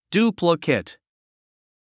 発音
djúːplikət　デュープリケト